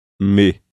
Maneira de articulação: nasal (abaixamento do véu palatino para que o ar que vem dos pulmões saia pela cavidade nasal)
Lugar de articulação: bilabial (lábio inferior e lábio superior).
Estado da glote: vozeado (há vibração das pregas vocais).